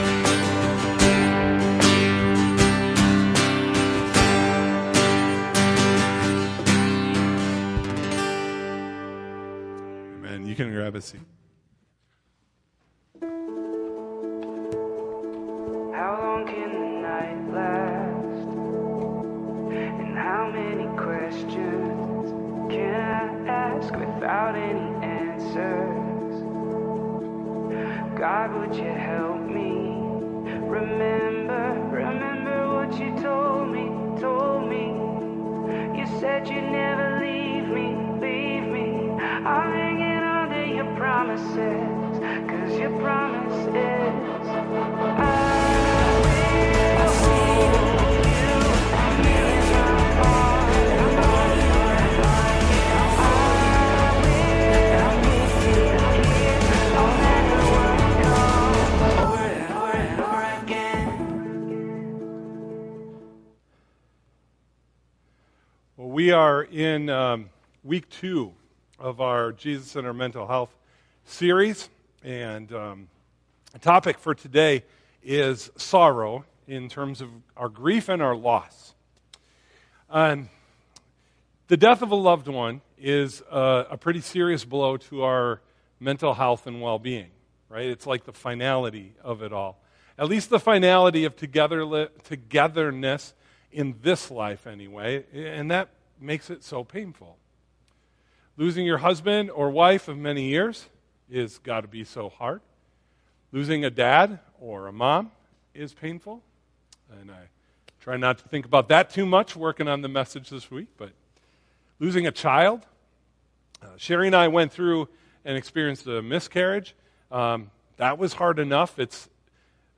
Sunday-Worship-Gathering-1-25-26.mp3